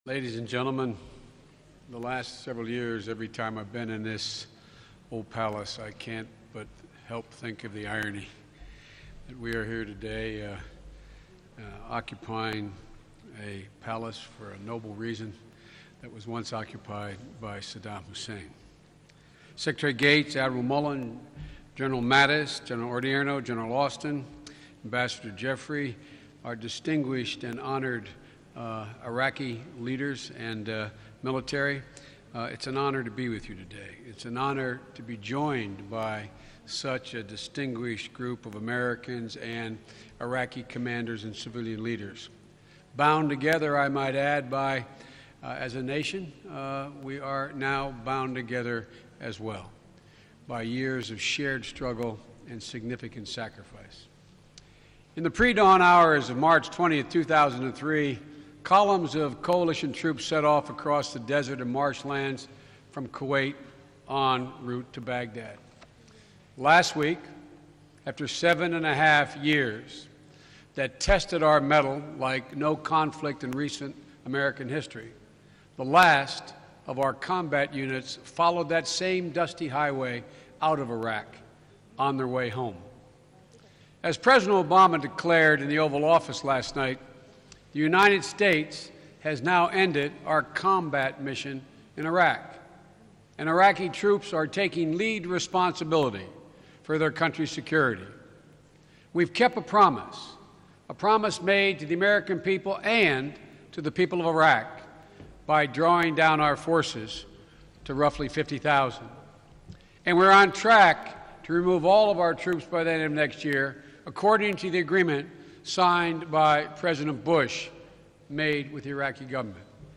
U.S. Vice President Joe Biden speaks at the Change of Command Ceremony for United States Forces-Iraq